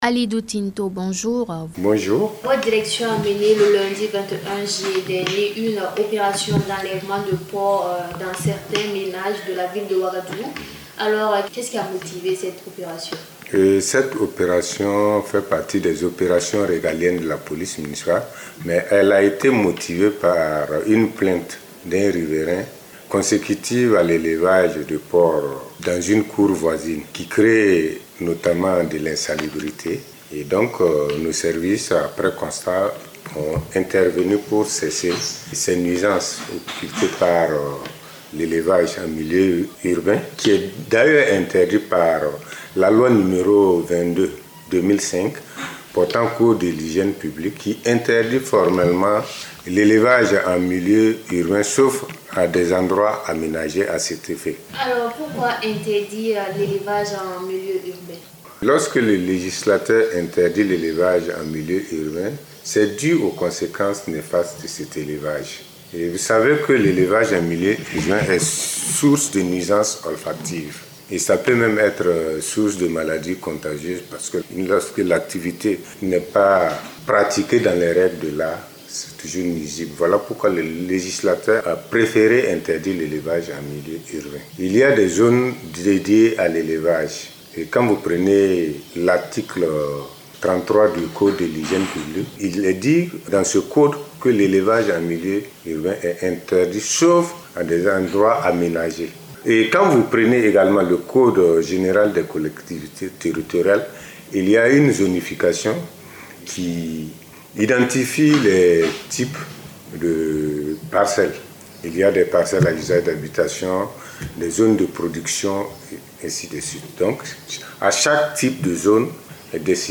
🔴Invité de la rédaction du 28 juillet 2025